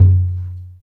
51 TND DRUM.wav